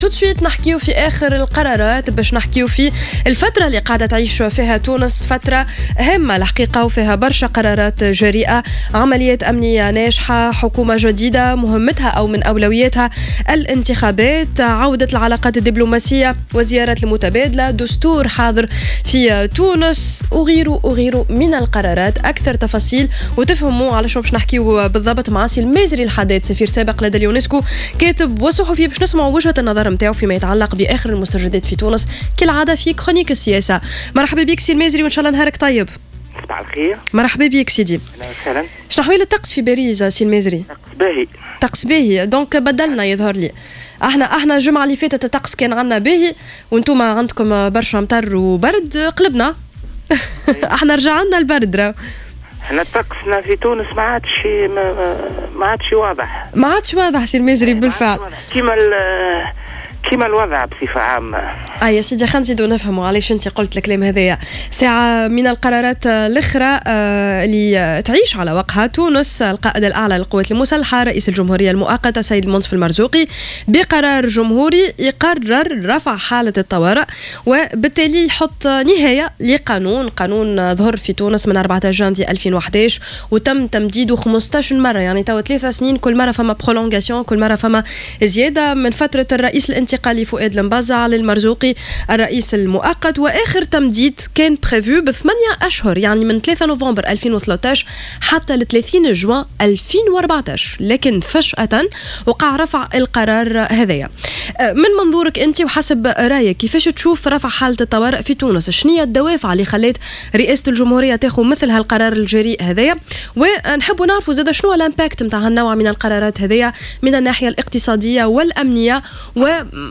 Mezri Haddad, écrivain et ancien ambassadeur de Tunisie auprès de l'Unesco, est revenu lors de son passage sur les ondes de Jawhara FM, vendredi 7 février 2014, sur la décision de la présidence de la république de lever l’état d’urgence en Tunisie.